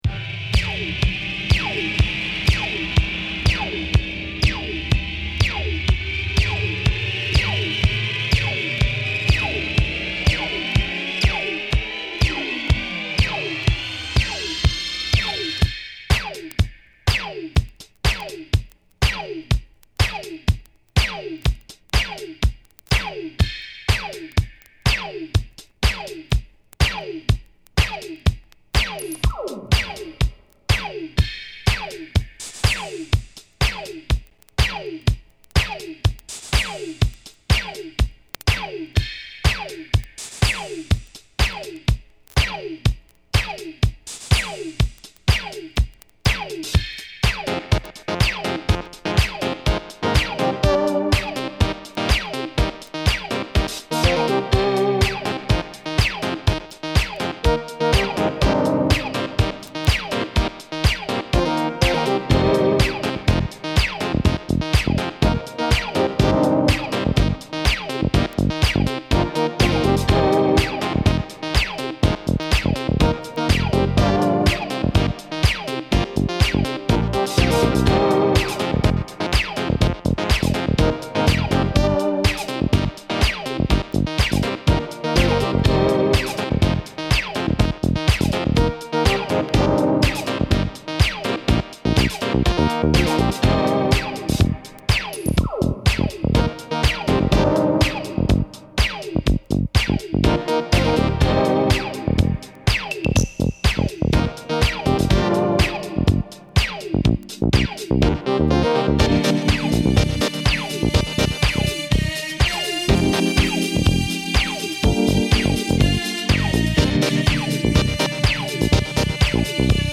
＊試聴はA→B1→B2です。